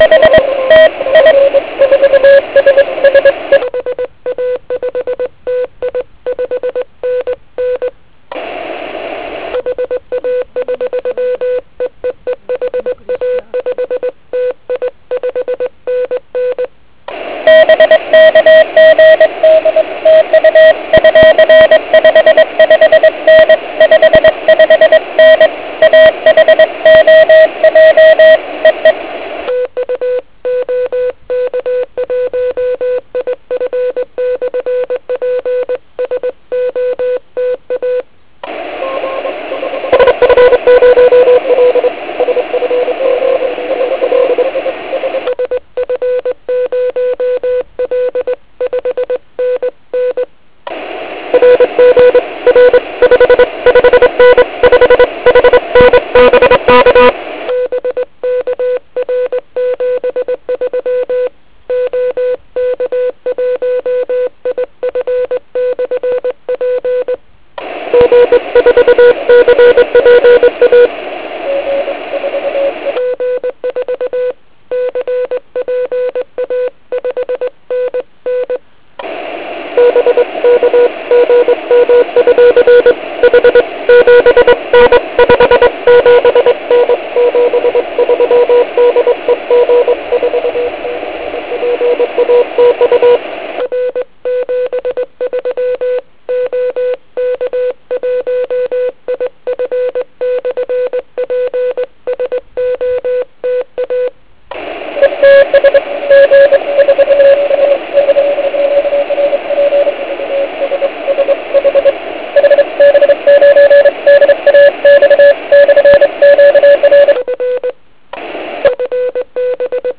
Pile upové pracoviště tentokrát bylo na mechu
Bohužel podmínky byly tentokrát velice podivné a vyvrcholily poruchou s docela slušnou Es vrstvou.